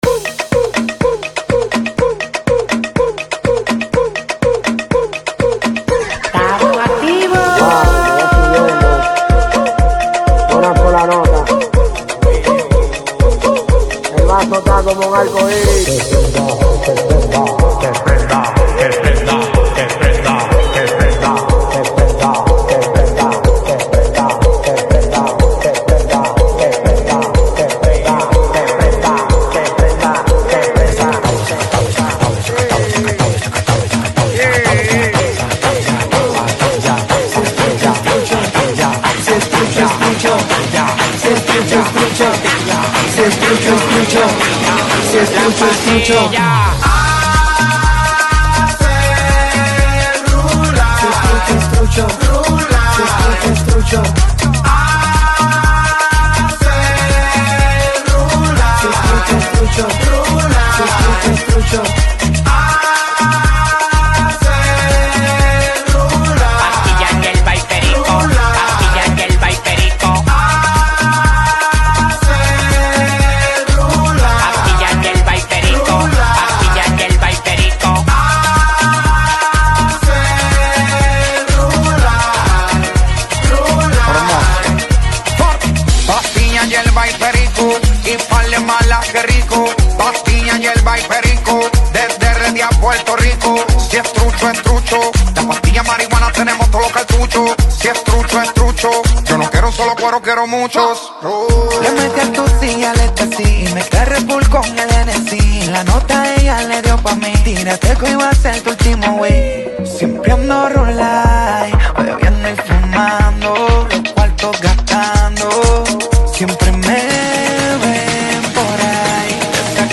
Genre: Dembow.